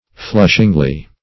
\Flush"ing*ly\